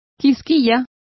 Complete with pronunciation of the translation of prawn.